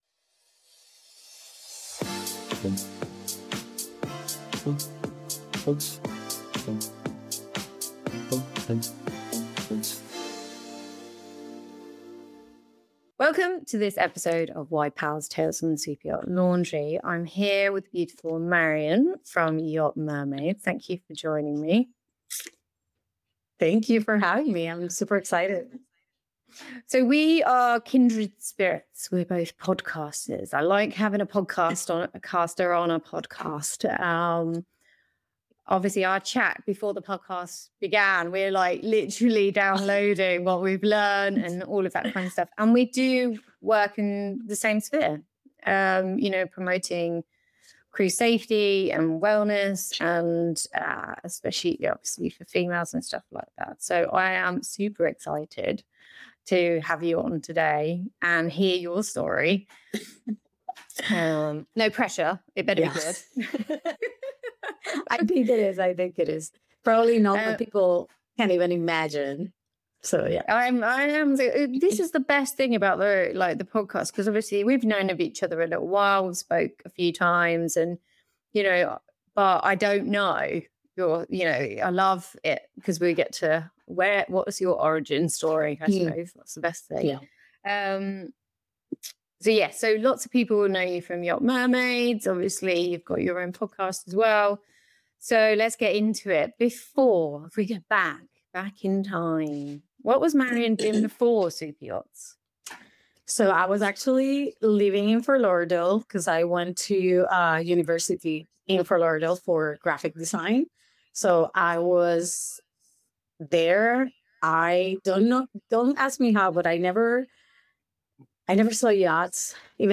🎧 Full interview available on all major podcast platforms.